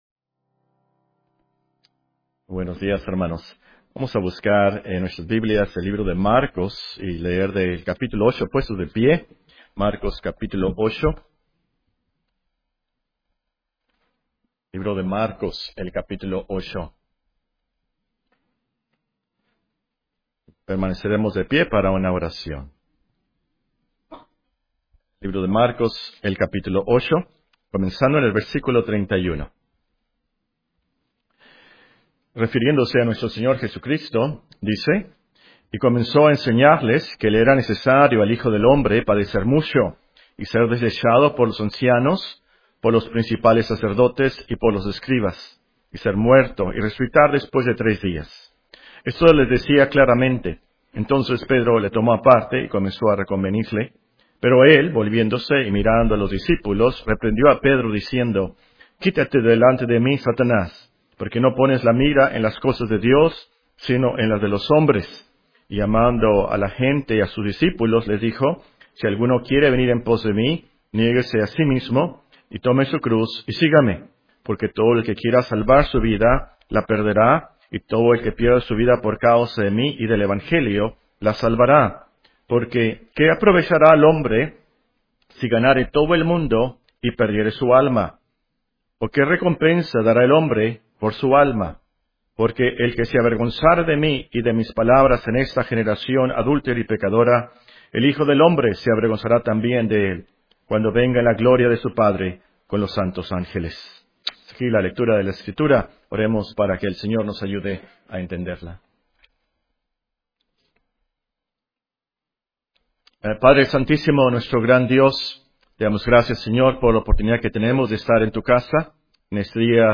Sermones Biblicos Reformados en Audio, porque la Fe viene por el Oir